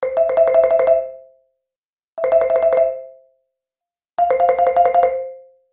Звуки тряски телефона
Мультяшный стиль nnРисованный вариант nnАнимационный образ nnКомиксный вариант